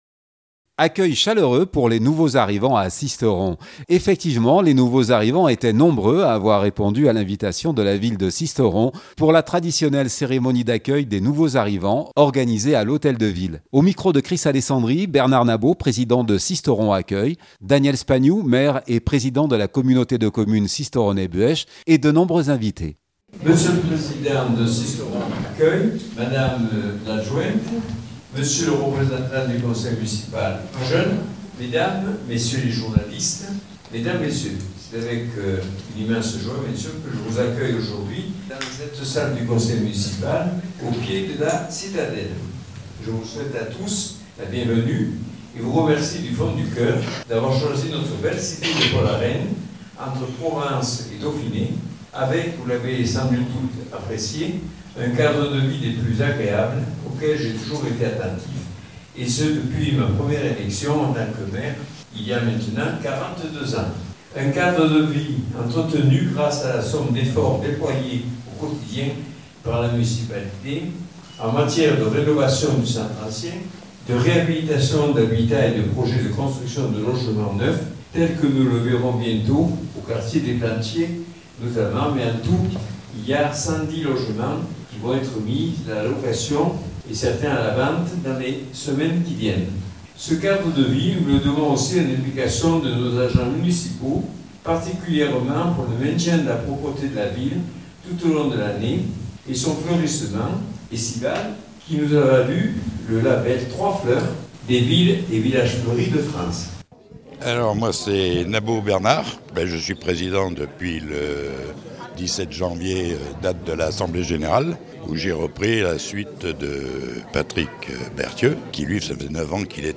Les nouveaux arrivants étaient nombreux à avoir répondu à l'invitation de la Ville de Sisteron et de Sisteron accueil pour la traditionnelle cérémonie d’accueil des nouveaux arrivants organisée à l'Hôtel de ville. L'occasion de découvrir les activités: rando, visites, excursions, voyages... proposées aux nouveaux arrivants et de se familiariser avec les services de la ville.